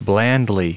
Prononciation du mot blandly en anglais (fichier audio)
Prononciation du mot : blandly